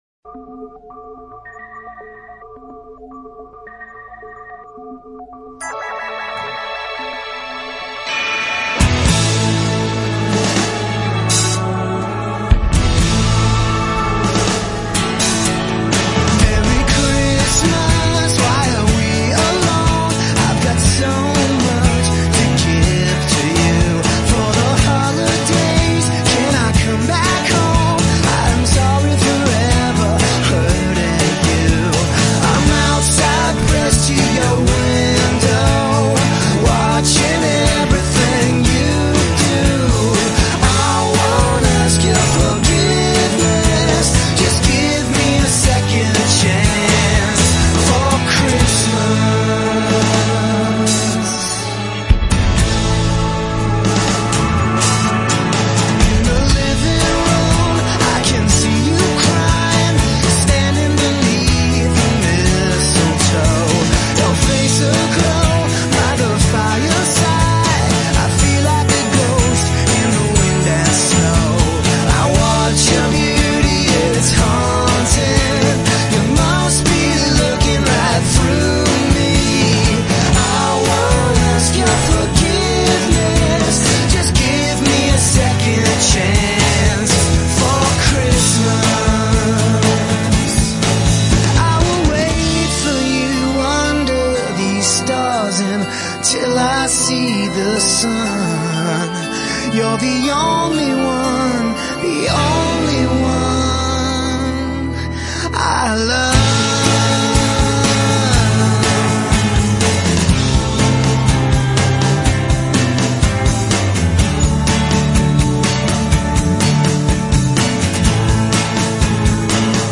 Just listen to these holiday-coated hooks!